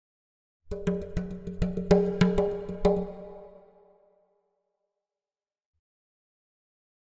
Drums Exotic Percussion Beat Finale 1